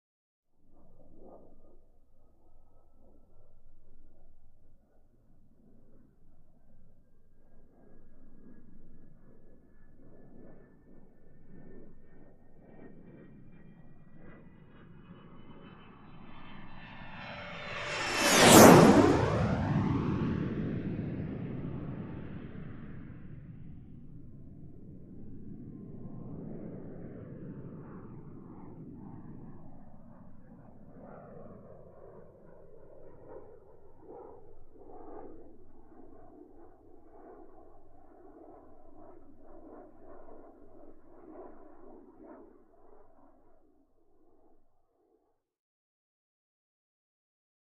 Marchetti: By Medium; Distant Jet Approach With Rising Whine, Extremely Fast By, Long Away. Medium To Distant Perspective. Jet.